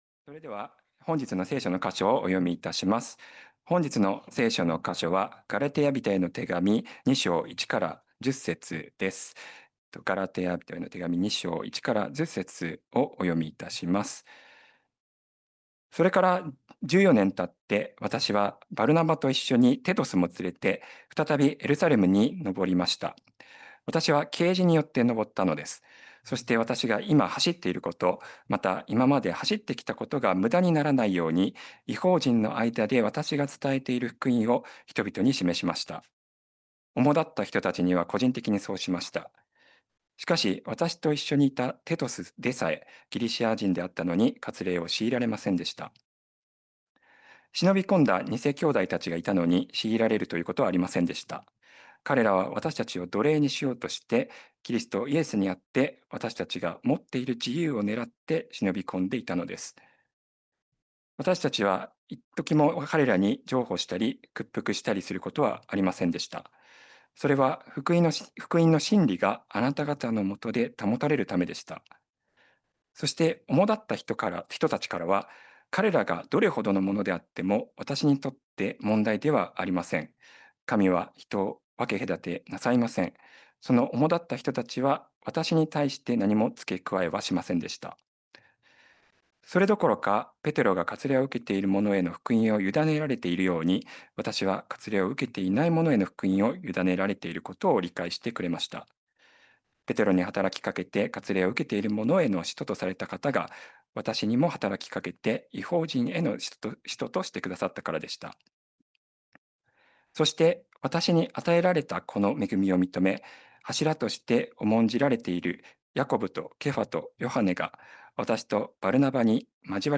2025年8月31日礼拝 説教 「真理のうちに一つ」 – 海浜幕張めぐみ教会 – Kaihin Makuhari Grace Church